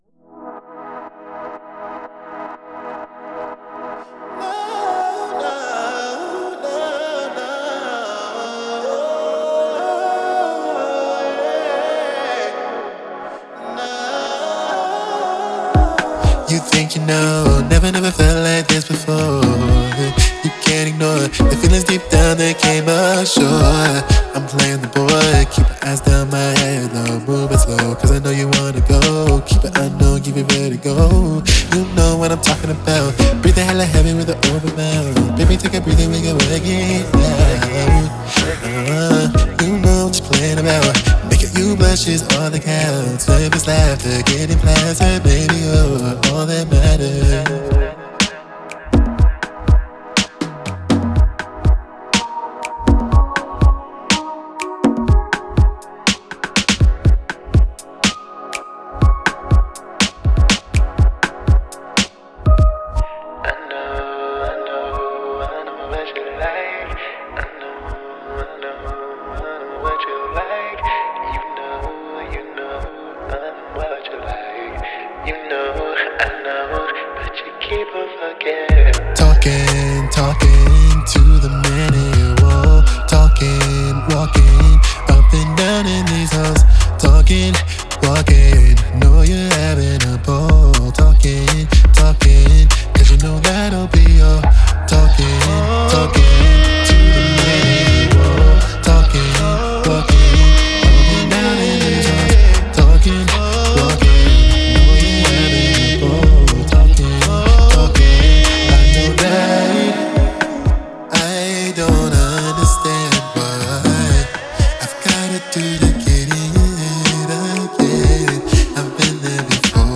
I used the TDR Nova plugin as a dynamic EQ to help with this by cutting out the frequencies that were competing.
I used the TDR Nova plugin again on the instrument to help create more space for the vocals. I also wanted to try to separate the kick and bass, so I cut out the fundamental freq of the kick in the bass and vice versa.